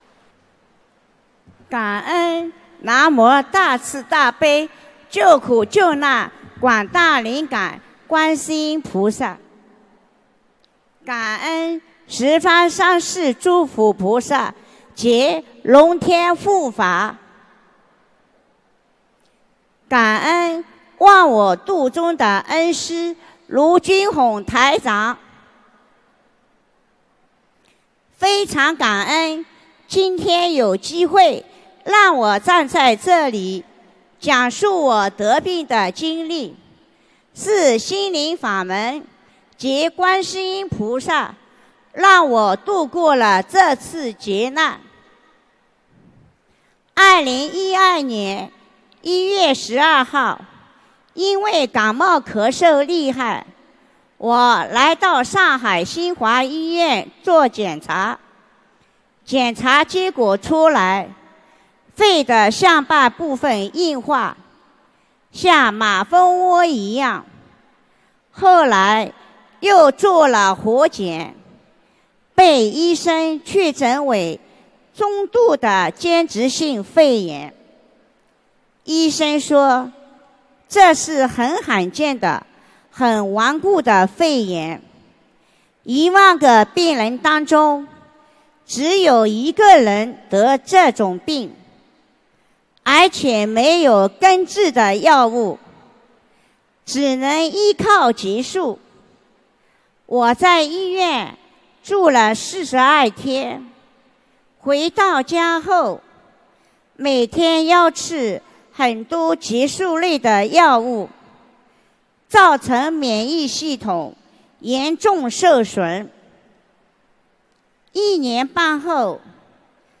马来西亚吉隆坡